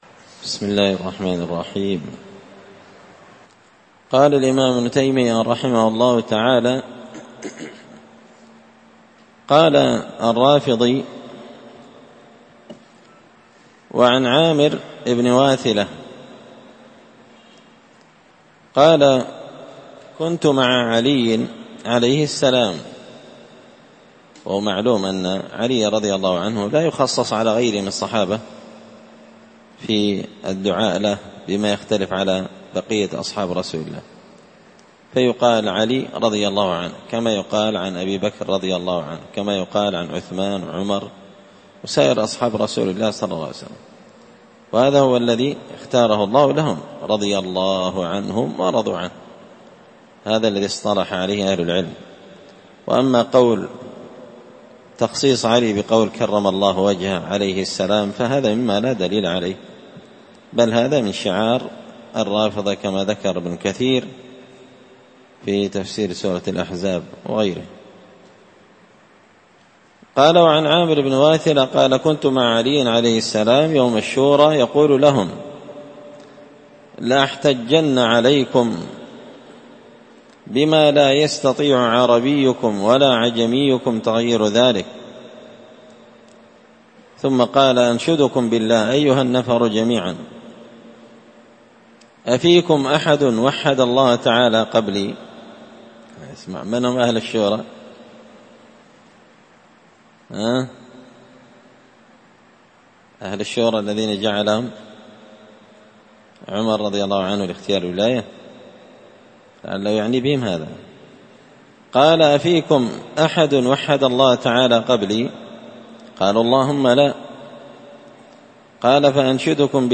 الدرس الحادي والتسعون (91)
مسجد الفرقان قشن_المهرة_اليمن